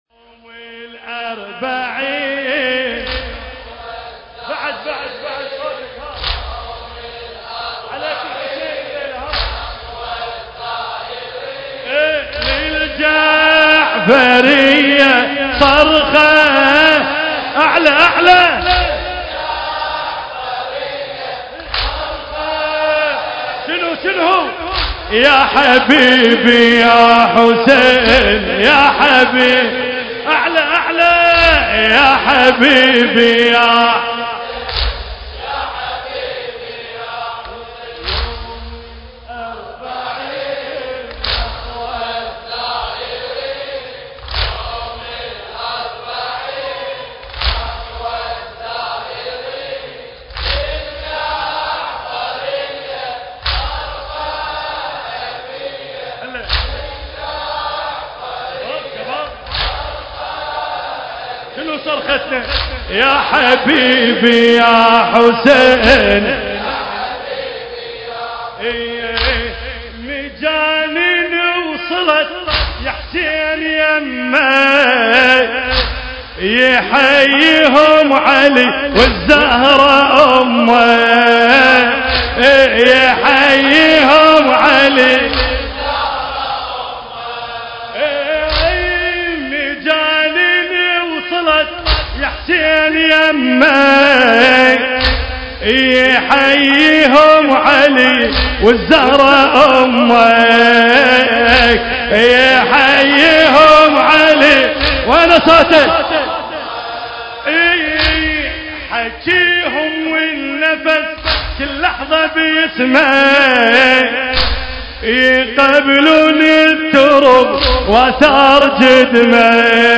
المراثي
المكان: حسينية كريم أهل البيت (عليهم السلام)
ذكرى أربعينية الإمام الحسين (عليه السلام)